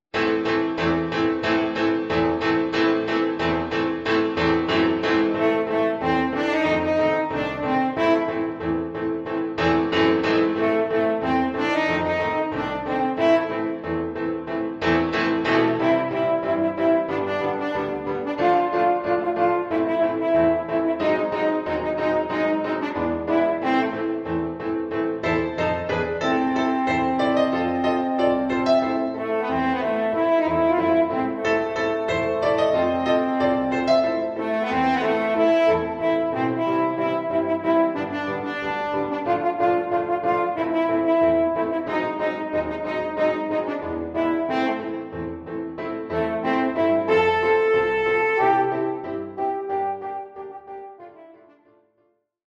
A tried and tested series for brass.